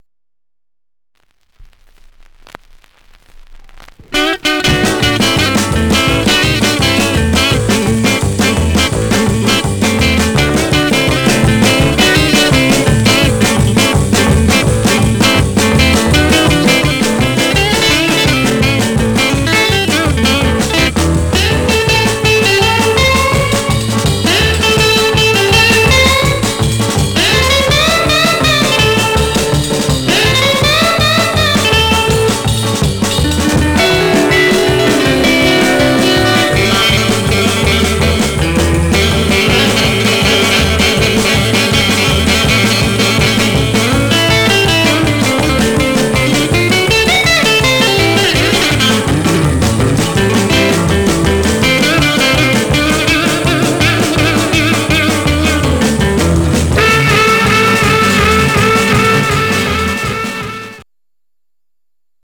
Stereo/mono Mono
R & R Instrumental Condition